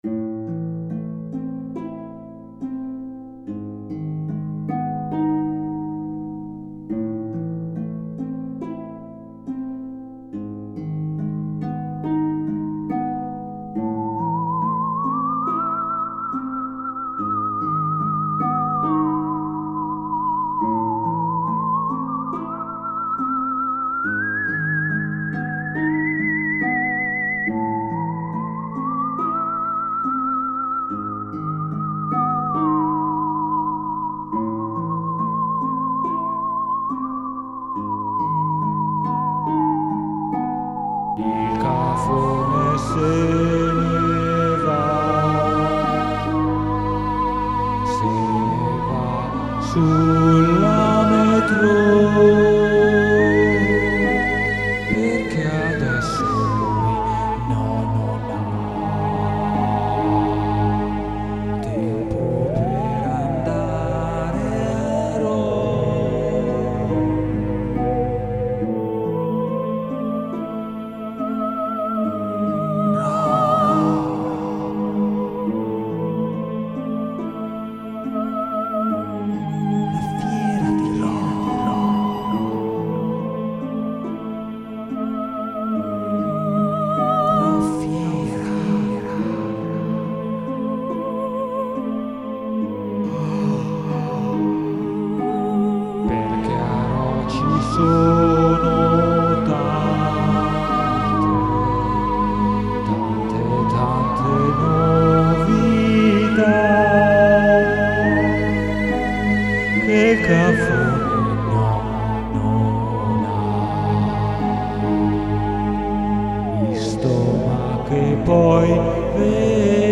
tastiere cupine e programming
voce dall'oltretomba